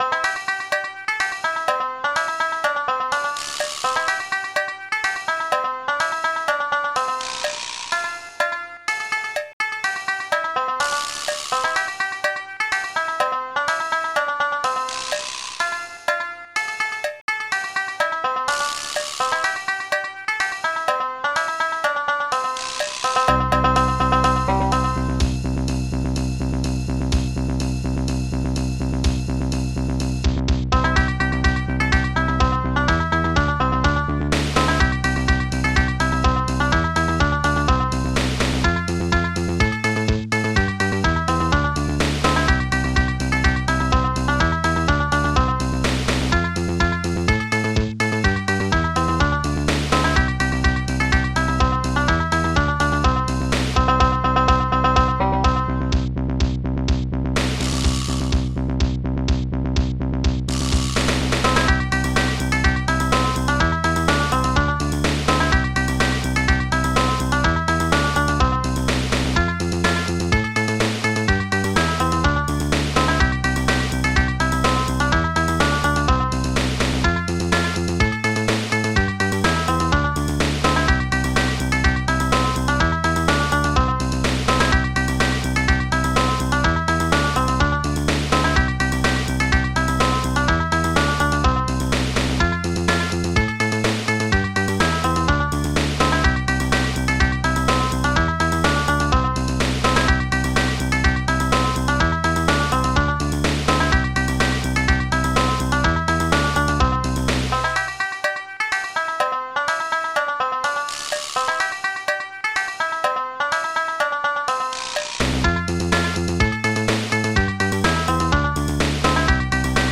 Protracker Module
st-02:banjo st-03:bassguitar9 st-05:bassdrum12 st-05:cowbell3 st-05:ridecymbal1 st-05:snake st-05:snare14